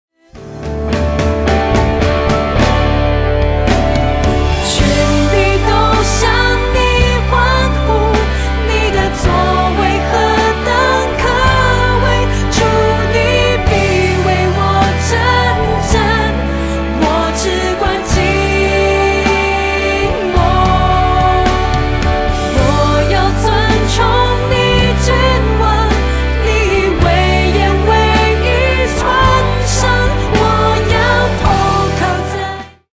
Mandarin Worship Album